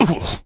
Category: Games   Right: Personal